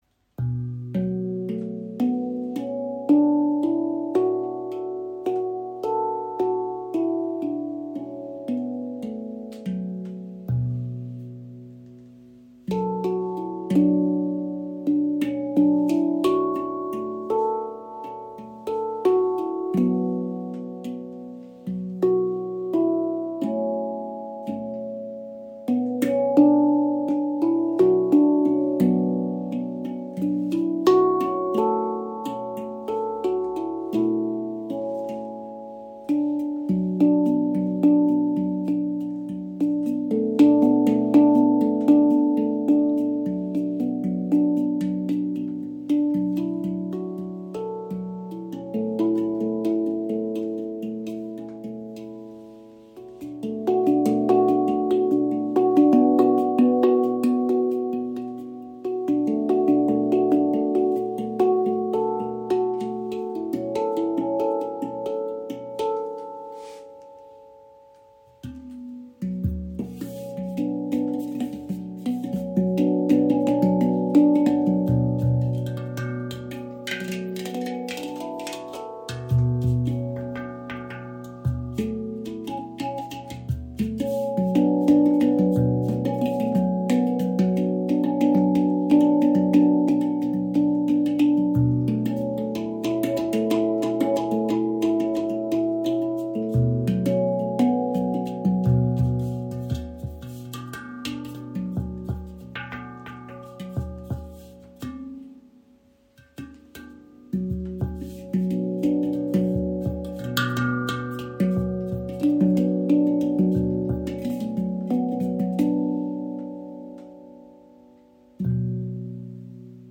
Handpan Noblet | B Aeolian | 9 Klangfelder – ruhig & erdend
• Icon B Aeolian – erdender Charakter (B – F# A B C# D E F# A)
• Icon Edelstahl-Handpan – langer Sustain, klare Ansprache für meditative Musik
B Aeolian bewegt sich in einer ruhigen, mollgeprägten Klangwelt. Die Stimmung wirkt ausgleichend, nach innen gerichtet und zugleich weich und offen. Aeolian entfaltet einen natürlichen Fluss ohne klangliche Schärfe. Die Töne greifen harmonisch ineinander und schenken Raum für Atem, Stille und Nachklang. Intuitives Spiel wirkt jederzeit stimmig und getragen.